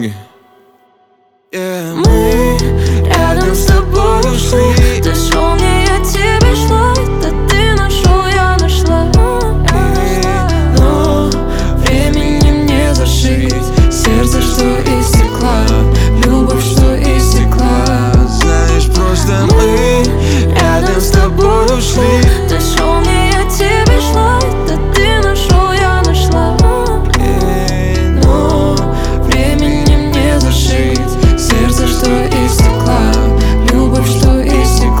Жанр: Рэп и хип-хоп